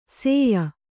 ♪ sēya